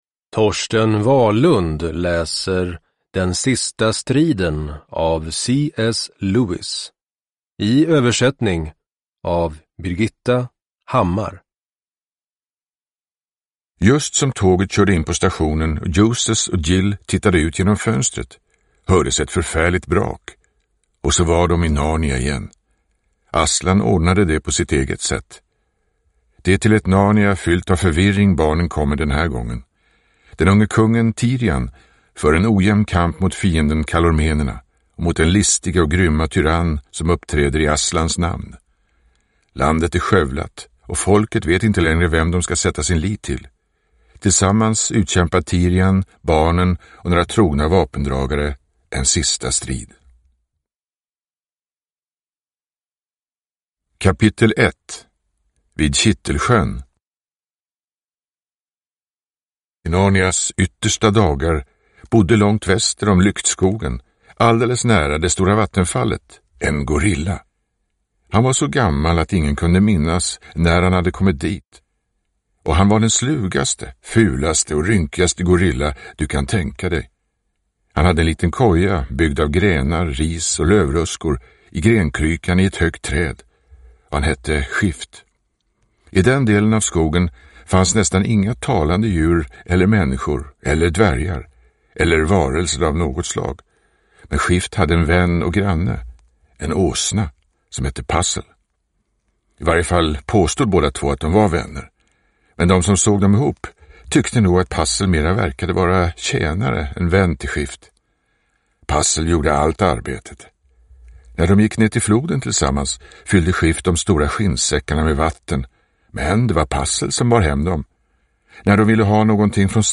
Den sista striden – Ljudbok – Laddas ner